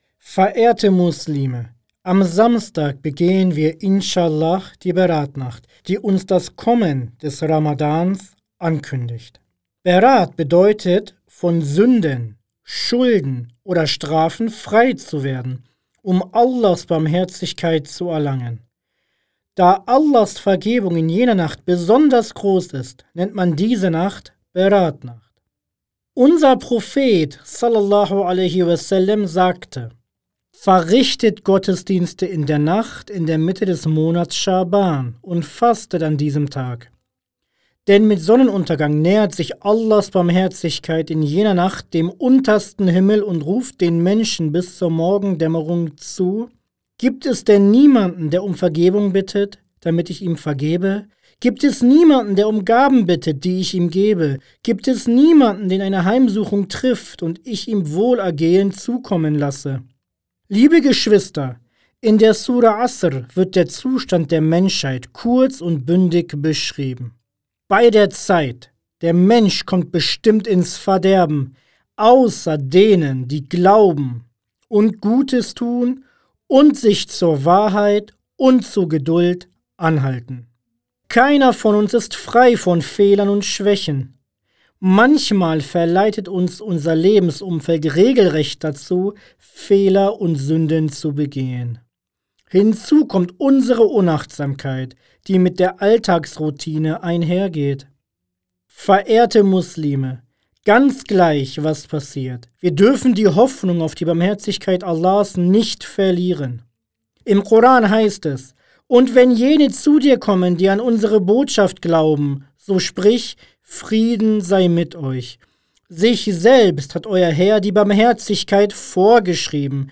Die wöchentlichen Freitagspredigten der Islamischen Gemeinschaft Millî Görüş